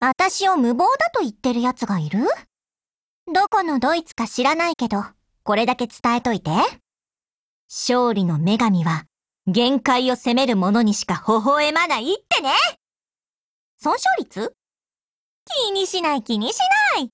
贡献 ） 协议：Copyright，其他分类： 分类:语音 、 分类:少女前线:P2000 您不可以覆盖此文件。
P2000_DIALOGUE1_JP.wav